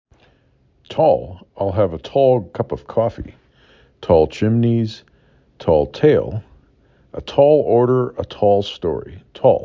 4 Letters, 1 Syllable
t aw l